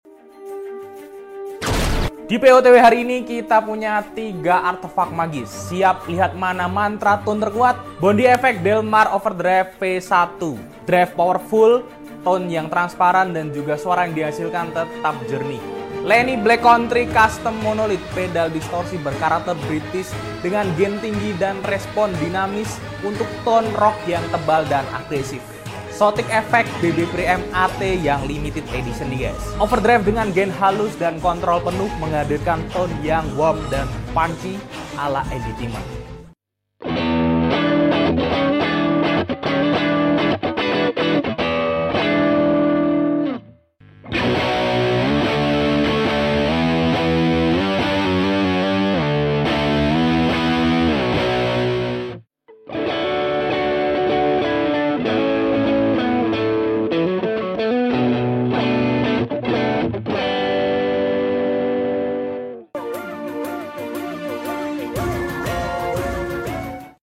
Ini dia 3 pedal yang bisa menyihir tone gitarmu menjadi suara yang menakjubkan!